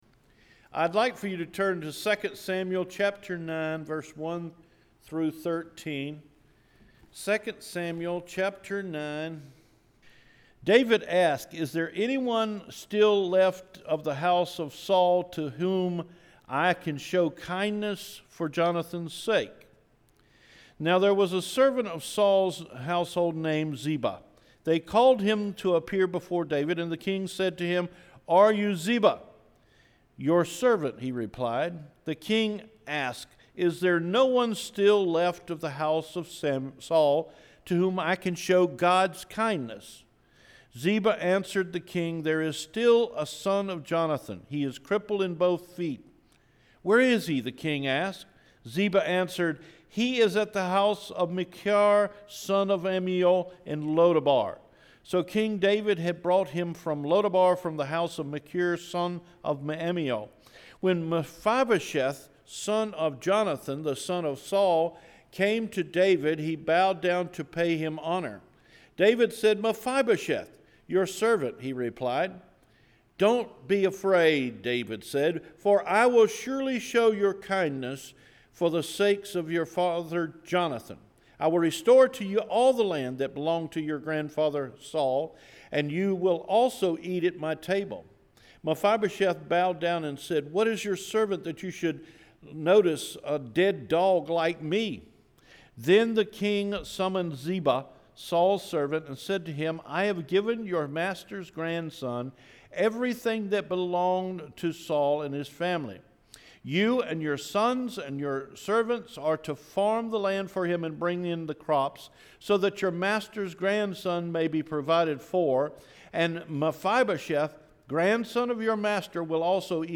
Recorded Sermons